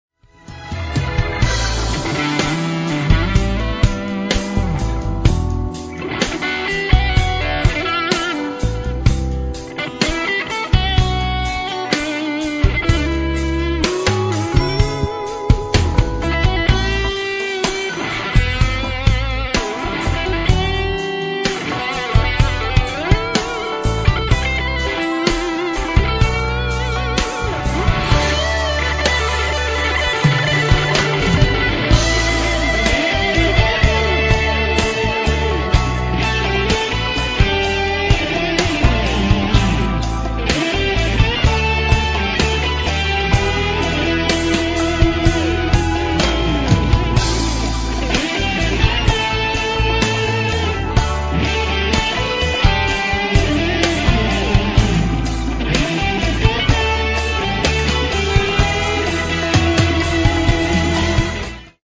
moderan blues feeling